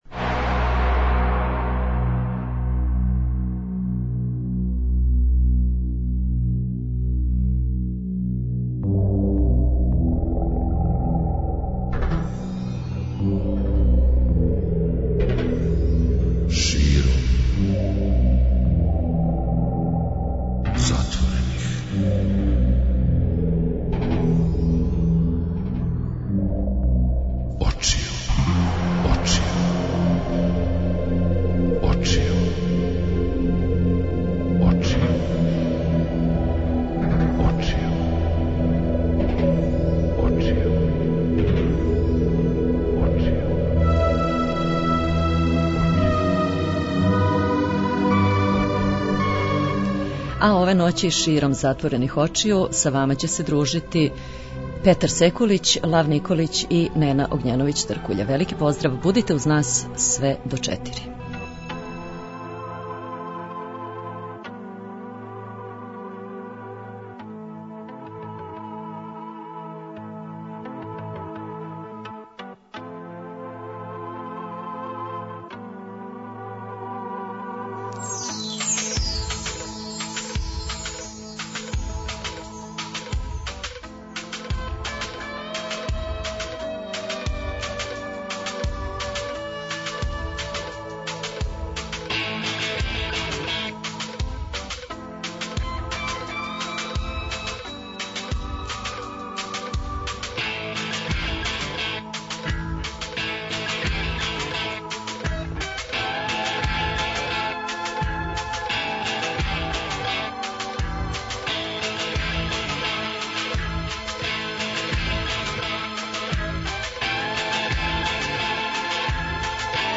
Ко је најлепши женски, а ко мушки вокал код нас и у свету? Сама тема обећава обиље добре музике, зато вас позивамо на дружење на самом почетку августа од поноћи до раних јутарњих сати.
преузми : 55.86 MB Широм затворених очију Autor: Београд 202 Ноћни програм Београда 202 [ детаљније ] Све епизоде серијала Београд 202 We care about disco!!!